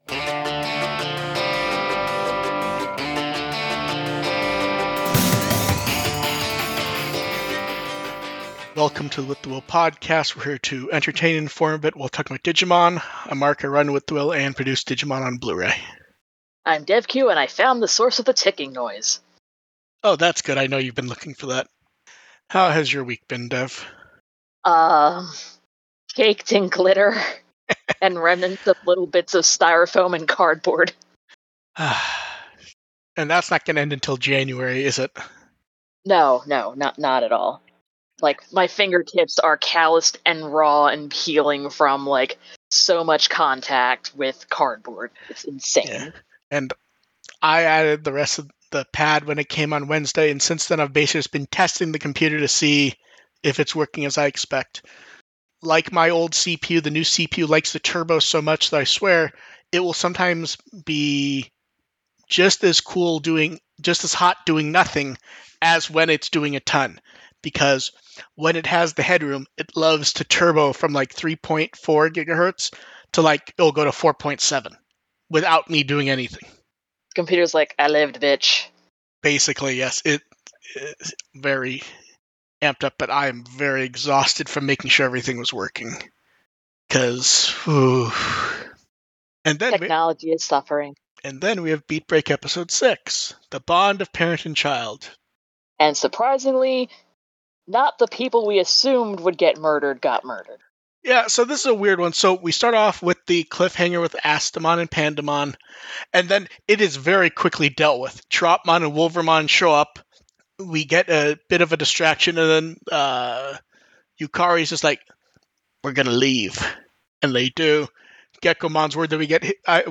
The podcast audio is the livestream clipped out, with an intro and outtro added, along with some tweaking to try and improve audio quality.